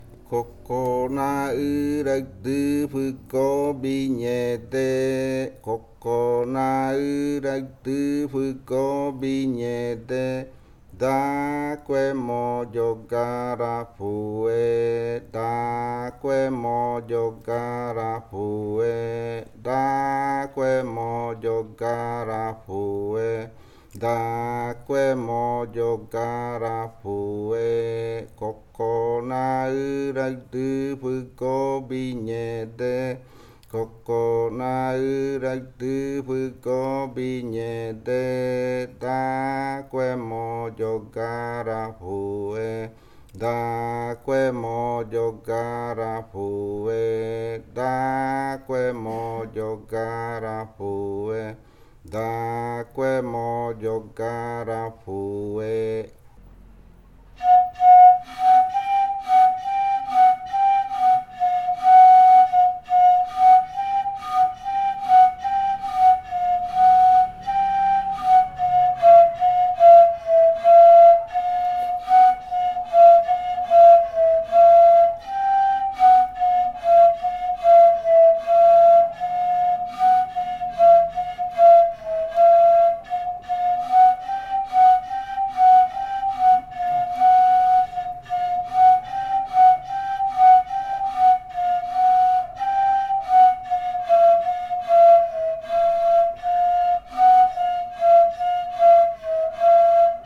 Leticia, Amazonas, (Colombia)
Canto Koko naɨraɨ (lengua murui) e interpretación del canto en pares de reribakui.
Koko naɨraɨ chant (Murii language) and performance of the chant in reribakui flutes.
Flautas de Pan y cantos de fakariya del grupo Kaɨ Komuiya Uai